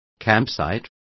Also find out how camping is pronounced correctly.